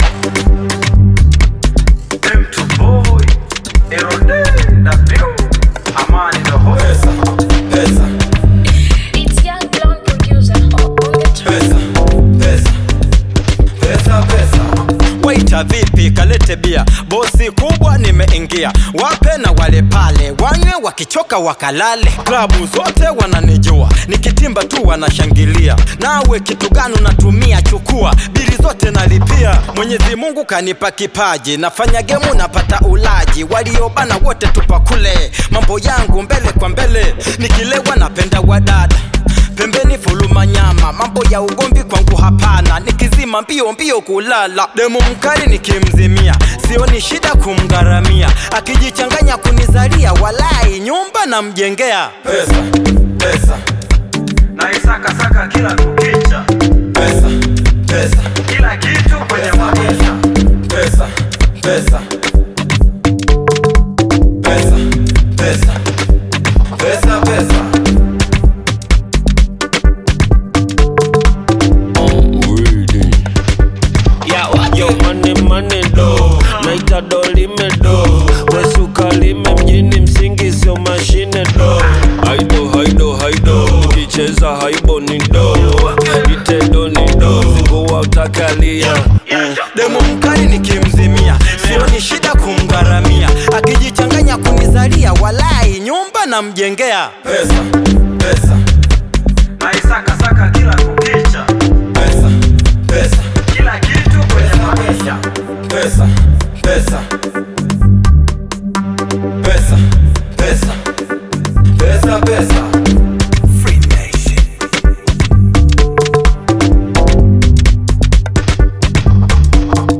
Bongo Flava music track
Bongo Flava song